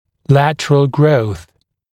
[‘lætərəl grəuθ][‘лэтэрэл гроус]боковой рост, рост в боковом направлении